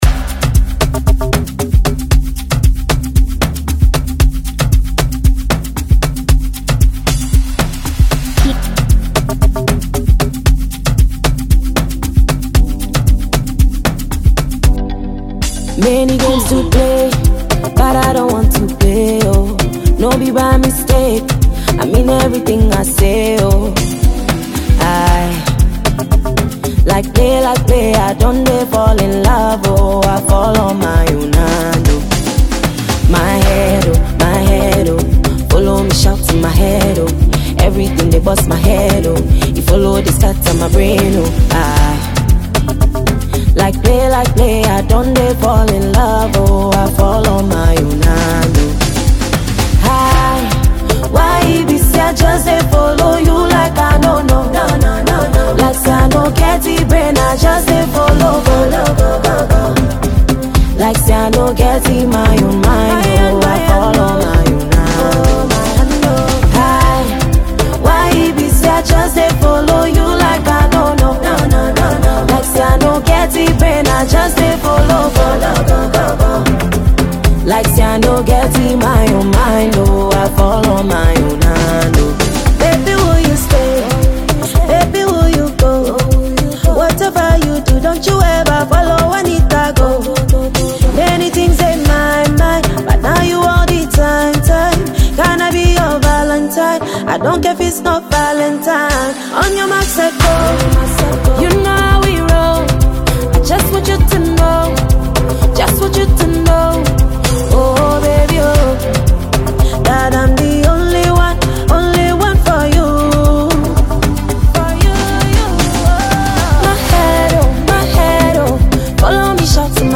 a Ghanaian songstress